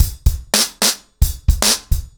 BlackMail-110BPM.11.wav